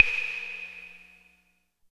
35 CLAVE  -R.wav